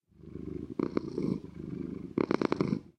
mob / cat / purr2.ogg
purr2.ogg